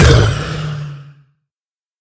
assets / minecraft / sounds / mob / wither / hurt2.ogg
hurt2.ogg